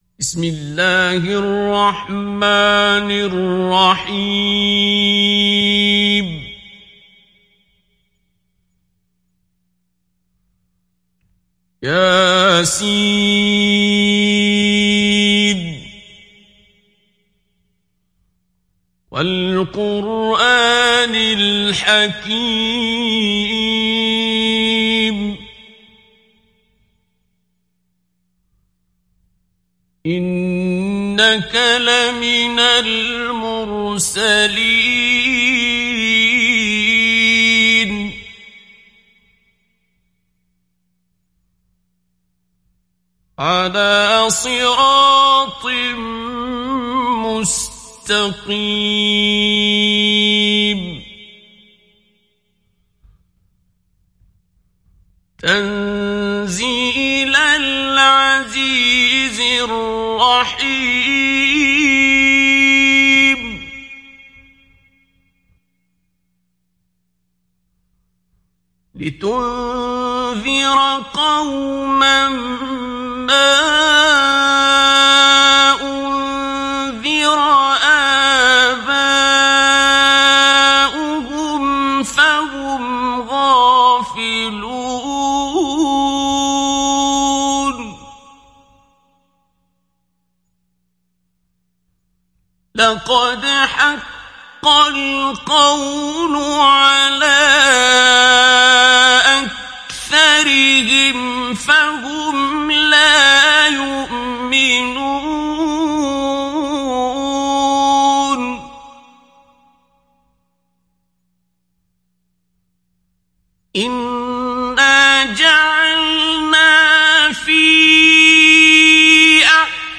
Sourate Yasin Télécharger mp3 Abdul Basit Abd Alsamad Mujawwad Riwayat Hafs an Assim, Téléchargez le Coran et écoutez les liens directs complets mp3
Télécharger Sourate Yasin Abdul Basit Abd Alsamad Mujawwad